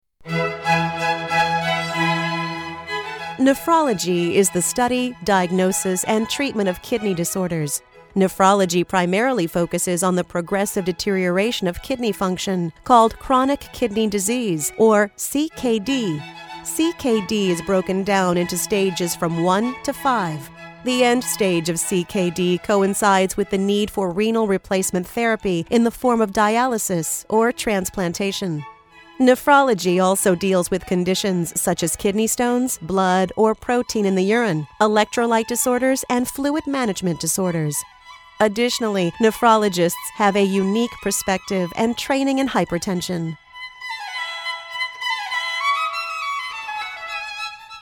Medical Messages On Hold are custom recorded marketing messages that will help grow your practice, help more patients in more ways, and help your practice sound more professional.
Medical-Landing-Nephrology.mp3